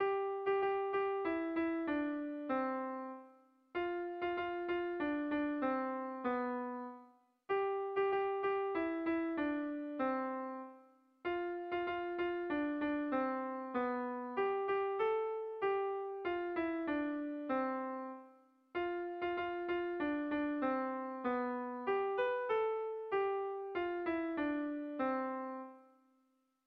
Dantzakoa
Seiko berdina, 3 puntuz eta 8 silabaz (hg) / Hiru puntuko berdina, 16 silabaz (ip)
A1A2B